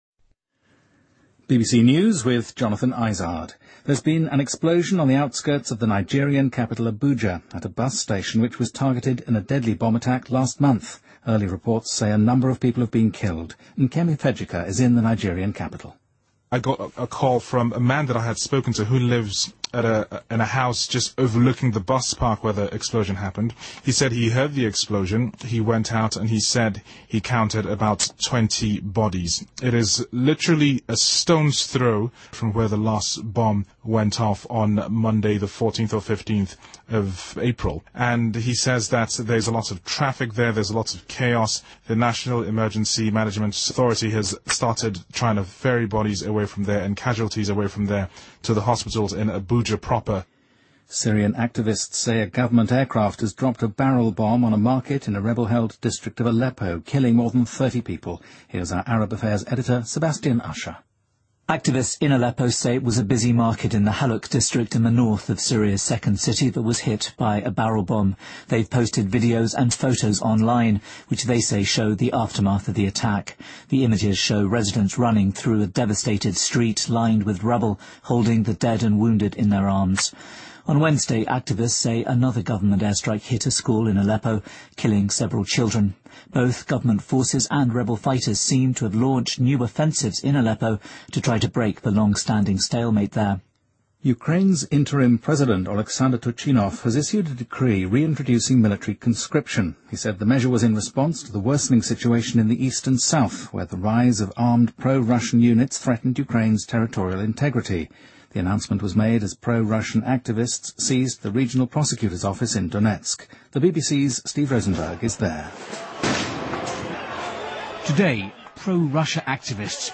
BBC news,2014-05-02